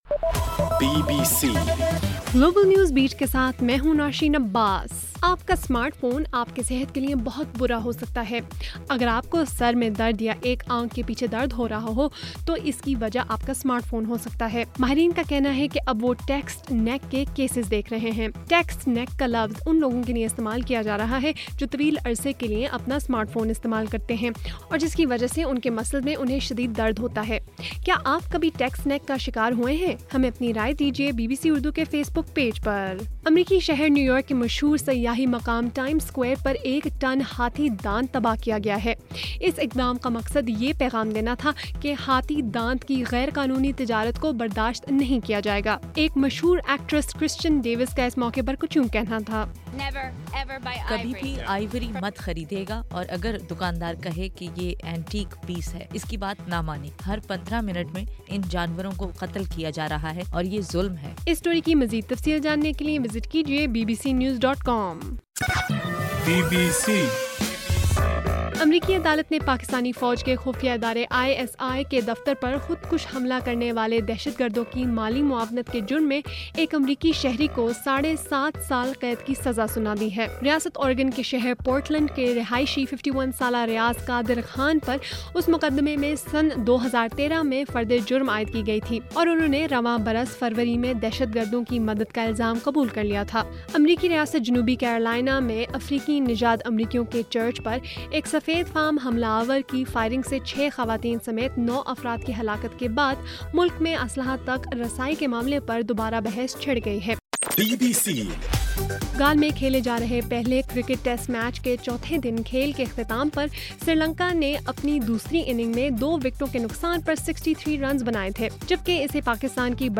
جون 20: رات 8 بجے کا گلوبل نیوز بیٹ بُلیٹن